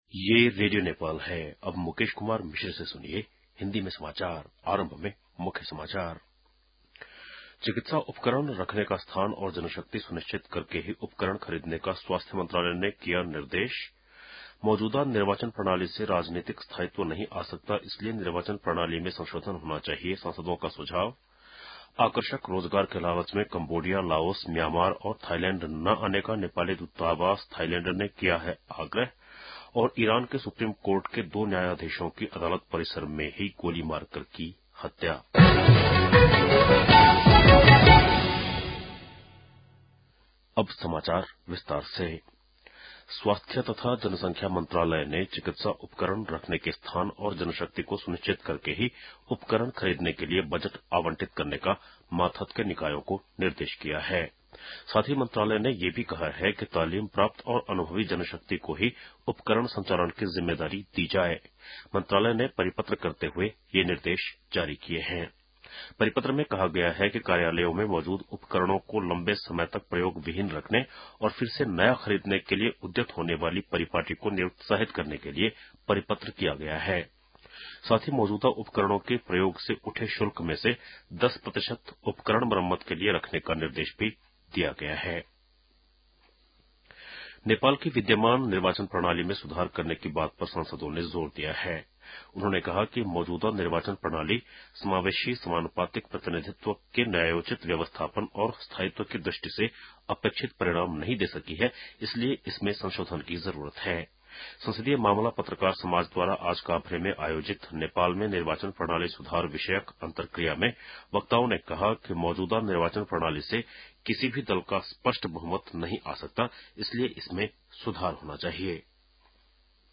बेलुकी १० बजेको हिन्दी समाचार : ६ माघ , २०८१
10-PM-Hindi-News-10-5.mp3